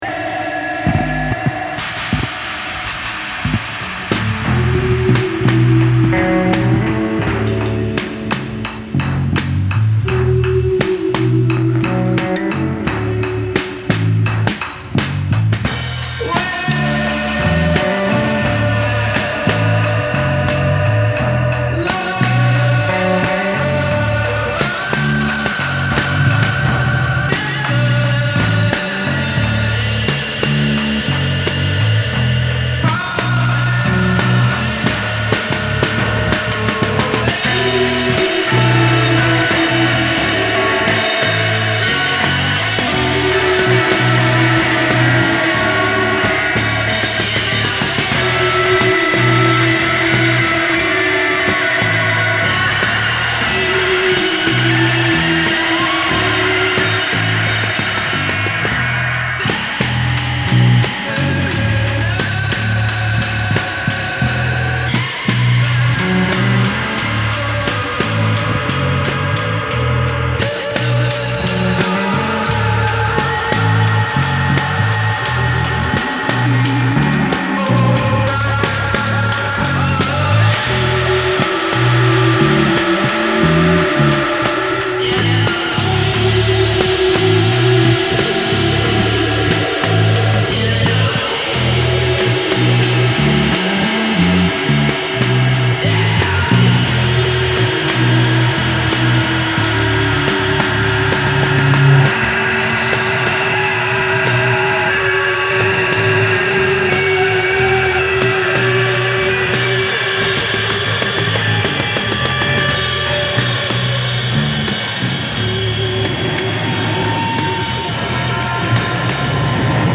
4 track cassette groove